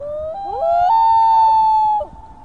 黄嘴潜鸟恐怖叫声